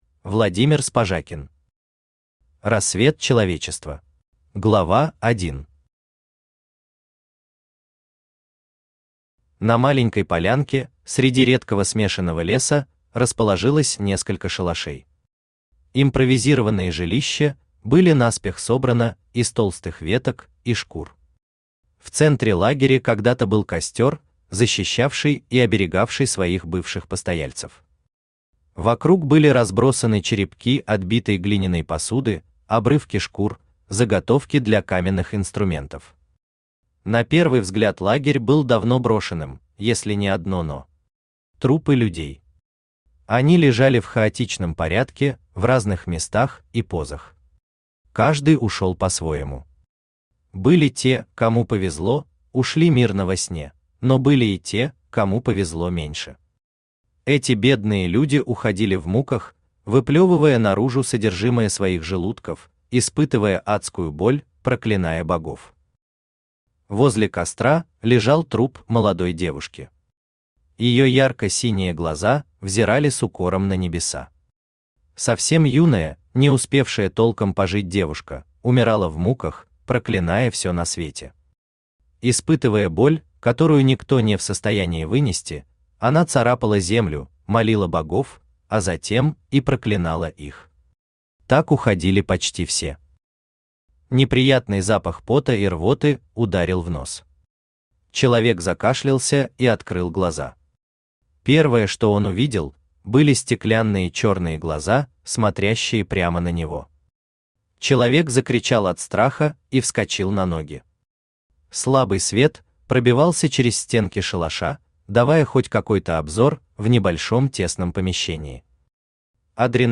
Аудиокнига Рассвет человечества | Библиотека аудиокниг
Aудиокнига Рассвет человечества Автор Владимир Викторович Спажакин Читает аудиокнигу Авточтец ЛитРес.